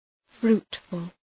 Προφορά
{‘fru:tfəl}